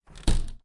Door Sounds » Door Open 3 Inch Slow
标签： Door Slow H4N
声道立体声